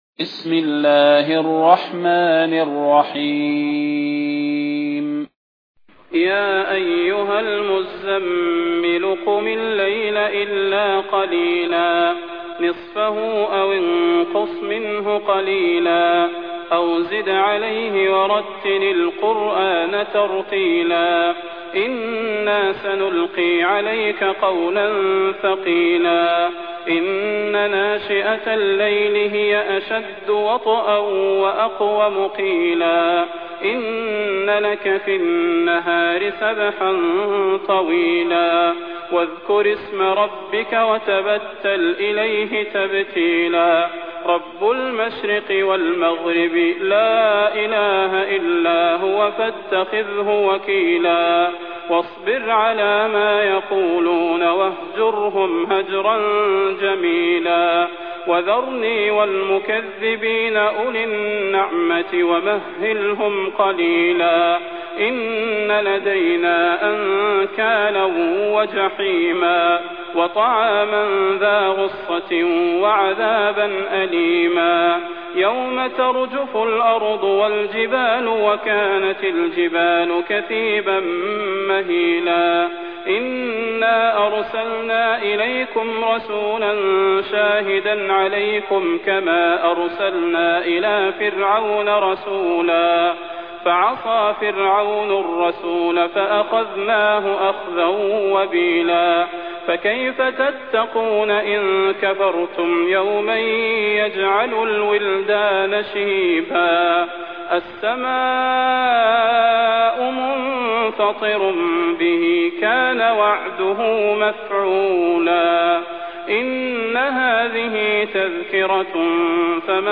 فضيلة الشيخ د. صلاح بن محمد البدير
المكان: المسجد النبوي الشيخ: فضيلة الشيخ د. صلاح بن محمد البدير فضيلة الشيخ د. صلاح بن محمد البدير المزمل